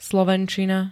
Zvukové nahrávky niektorých slov
e32y-slovencina.ogg